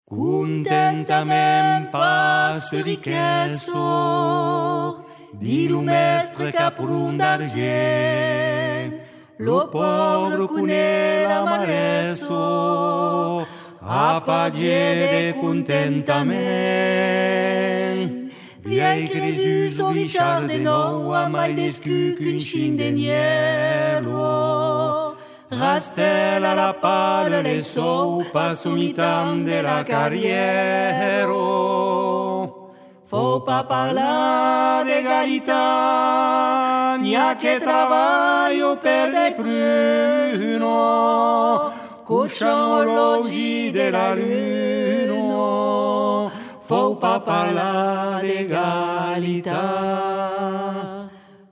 Les arrangements sont excellents